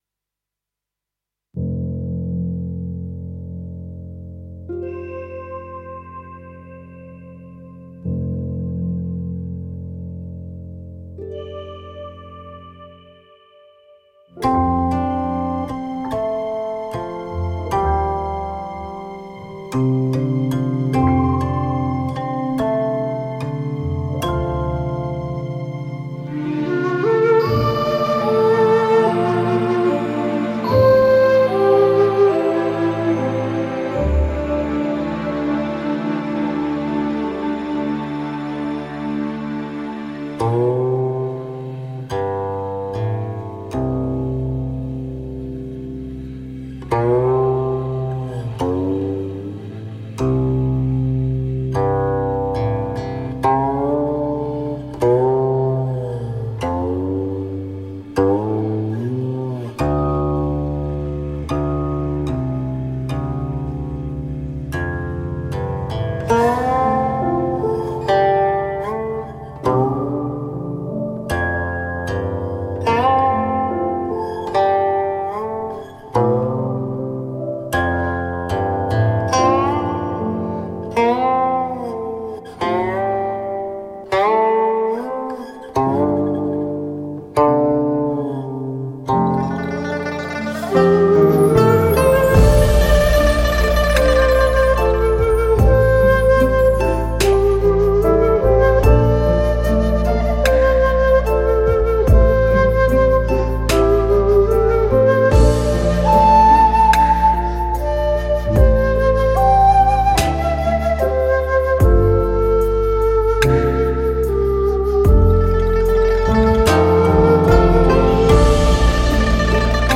佛音 冥想 佛教音乐 返回列表 上一篇： 一袖云(纯音乐